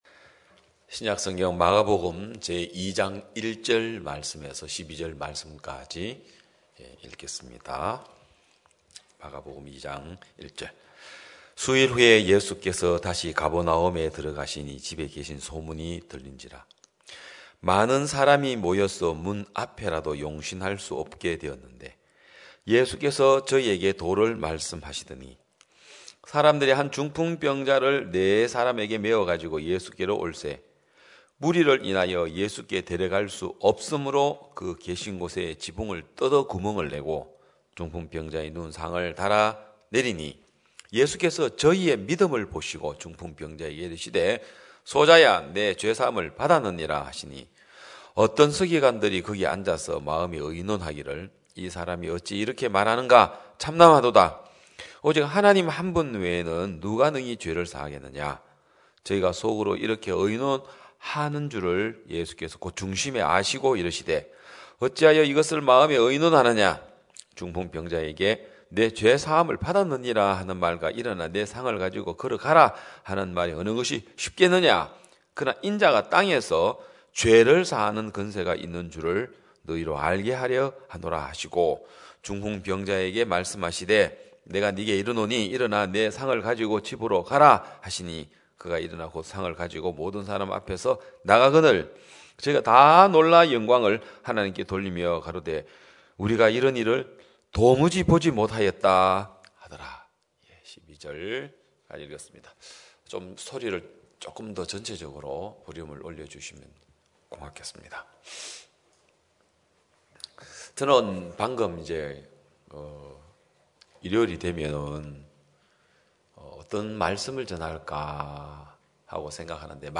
2021년 10월 17일 기쁜소식양천교회 주일오전예배